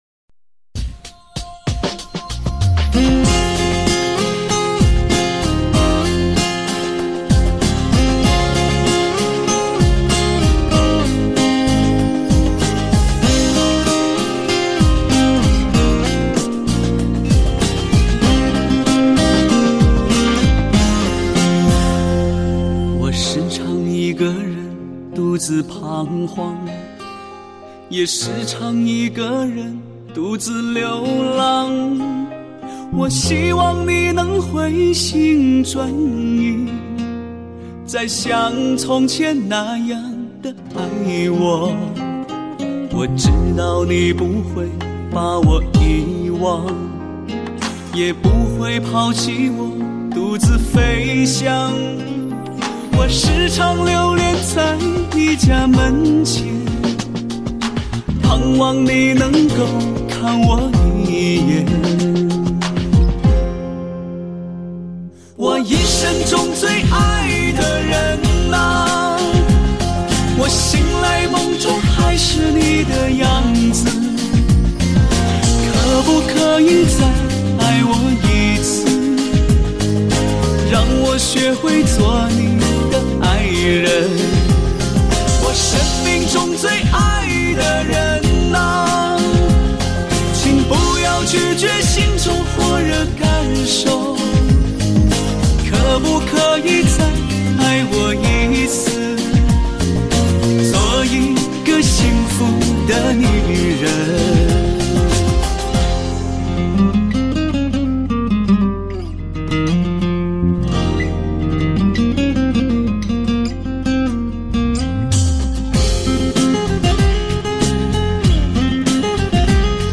歌曲里的悲伤情歌，结合了文字和音乐的双重催泪效果。
煽情的文字、煽情的旋律，如何叫人不悲伤。